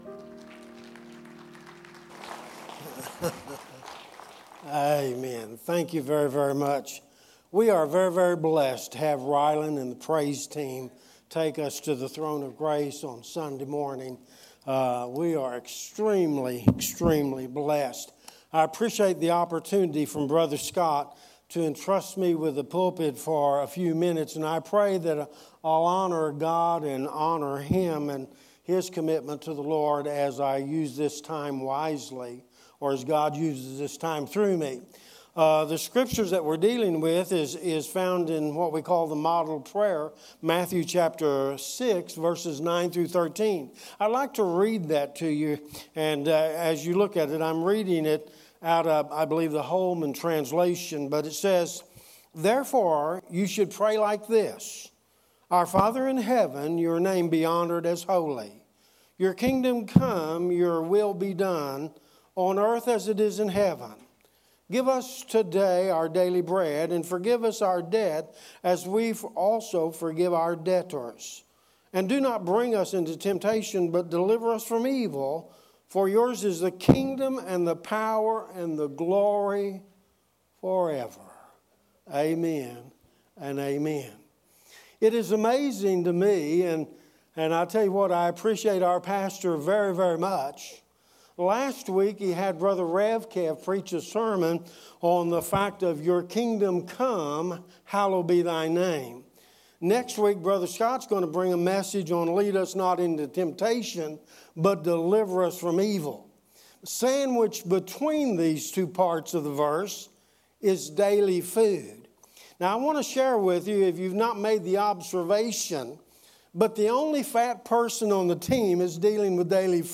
Sermons | Central Baptist Church Owasso